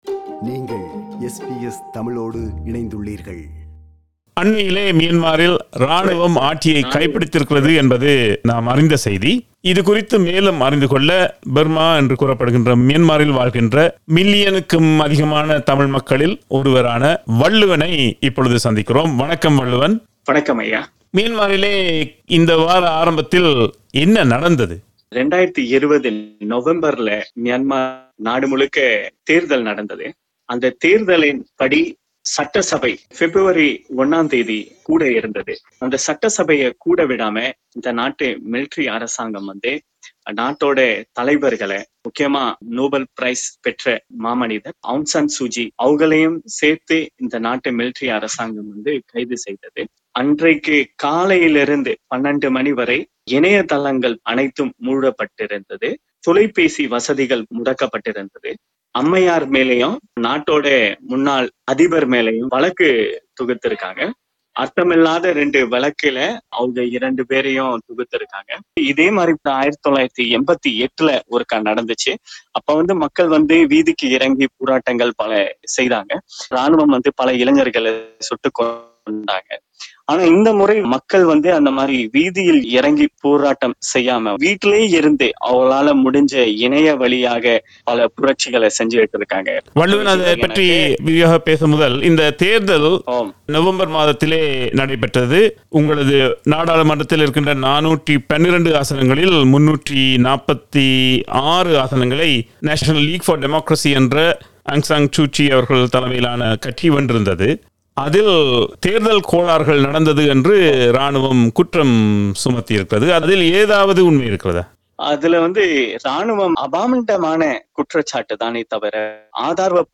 மியான்மார் (பர்மா) நாட்டில் என்ன நடக்கிறது? - ஒரு தமிழரின் வாக்குமூலம்